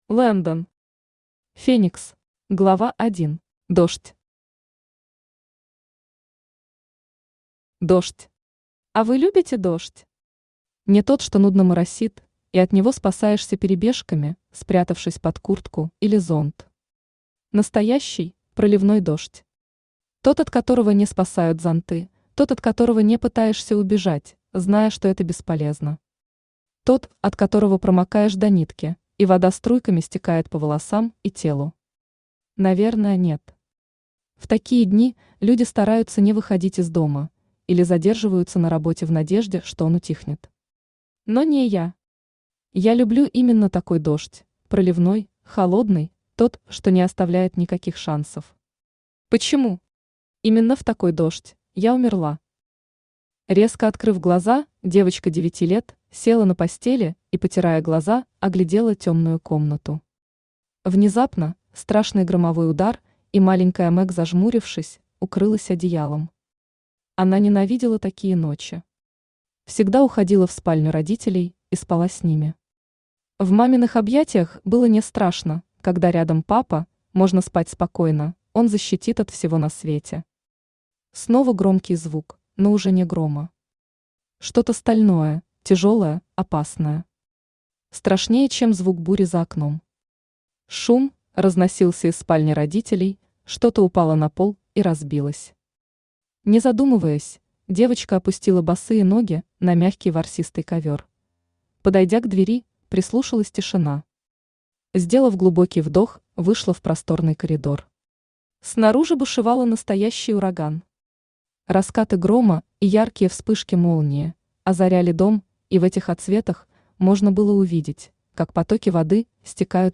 Aудиокнига Феникс Автор Landen Читает аудиокнигу Авточтец ЛитРес.